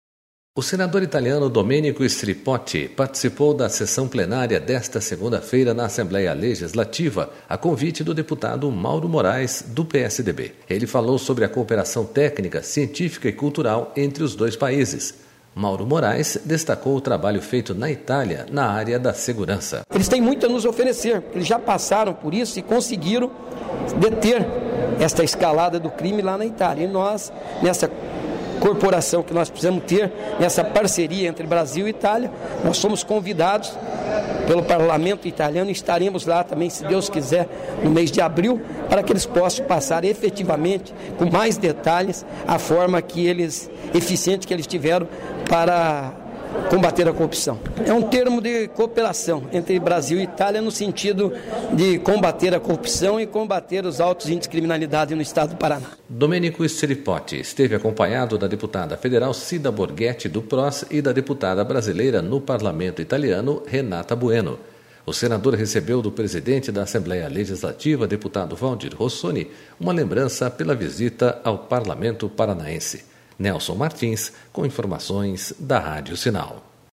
Assembleia recebe visita de senador italiano durante sessão plenária